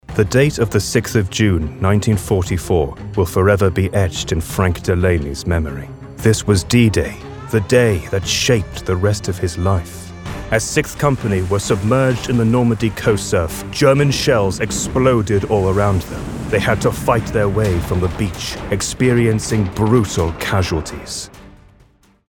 Engels (Brits)
Diep, Natuurlijk, Veelzijdig, Vertrouwd, Vriendelijk
Audiogids